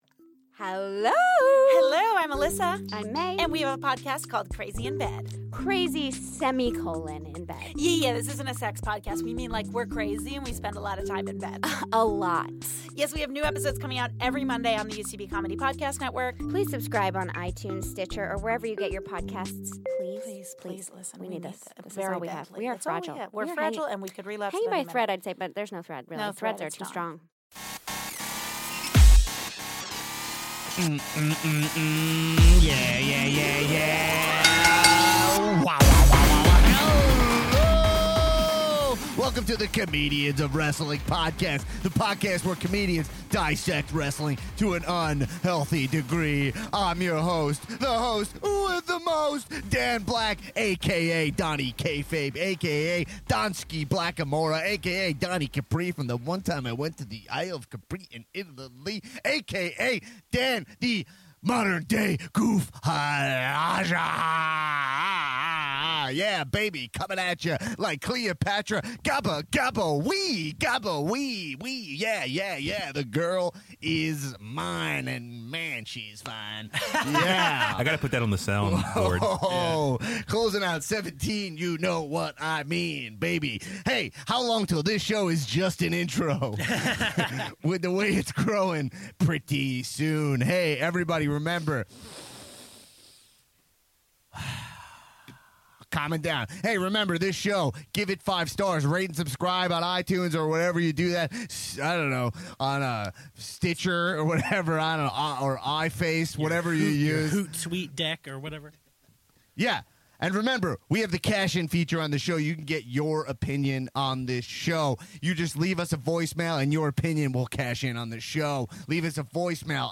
IT’S OUR LAST STUDIO EPISODE FOR THE YEAR, COW-HEADS!